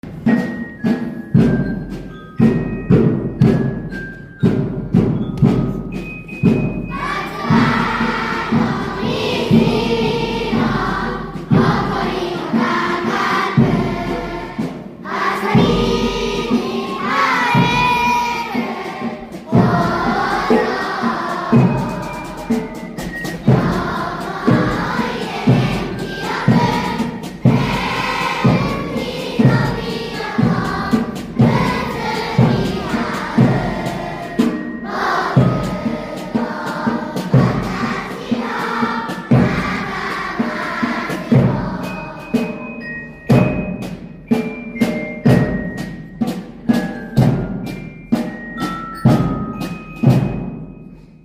1224♪校歌♪終業式
1224終業式　校歌.mp3